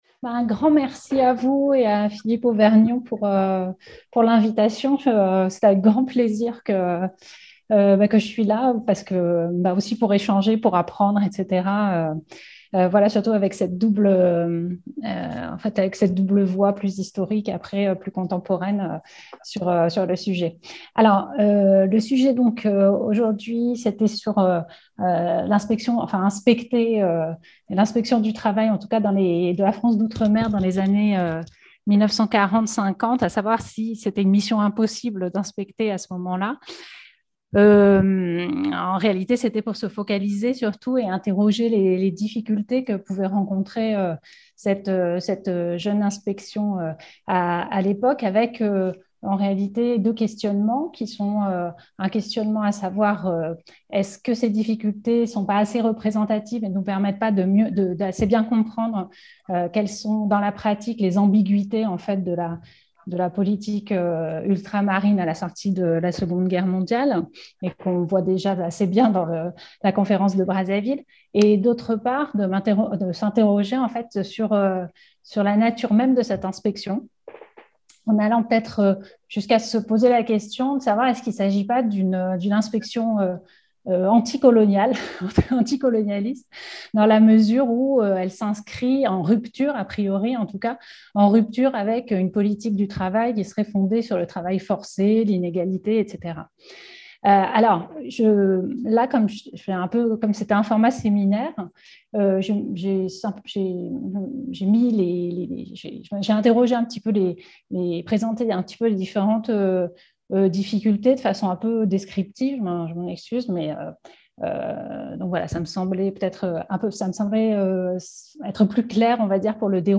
Cette intervention analyse le rôle méconnu et pourtant primordial de l’inspection générale du travail (et des lois sociales) de la France d’Outre-mer en Afrique au sortir de la guerre jusqu’aux années 1950. Cette jeune administration va s’émanciper et commencer à mettre en place de nouvelles mesures (surtout sociales) en faveur des droits des salariés.